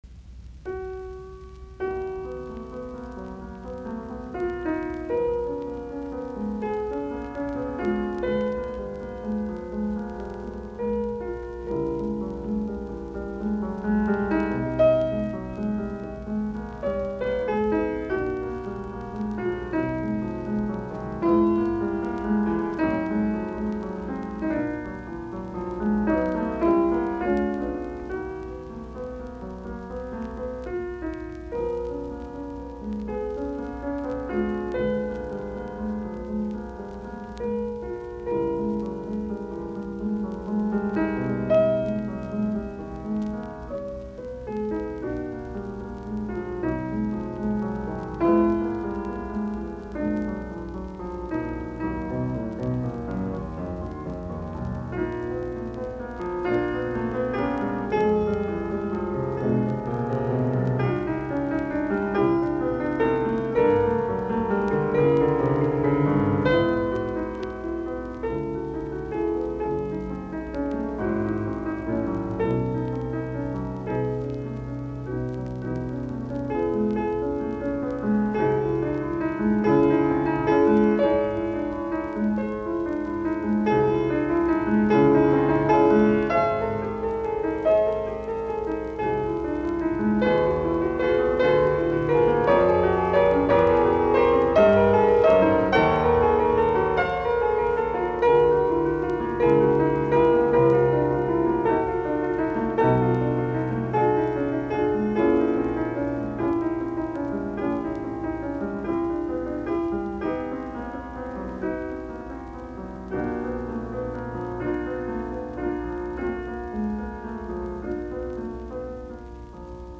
Etydit, piano, op10
Soitinnus: Piano.